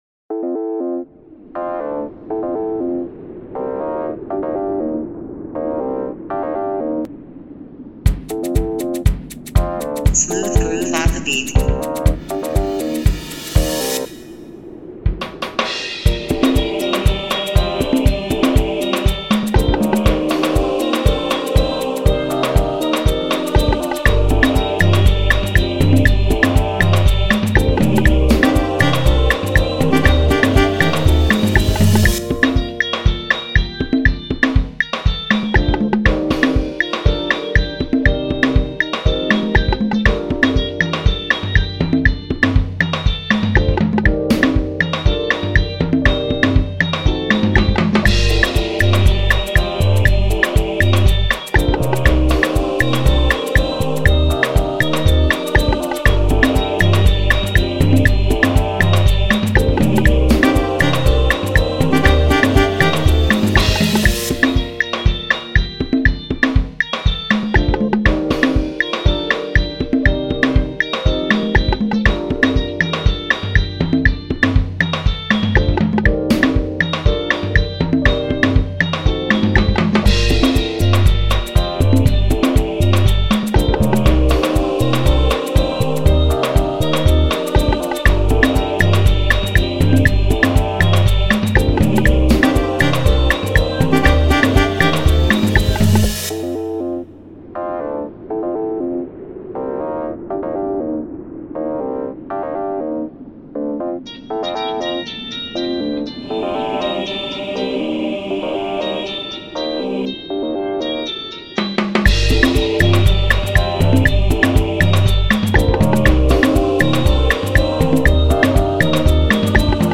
sweet afro vibes the naija way in its purest form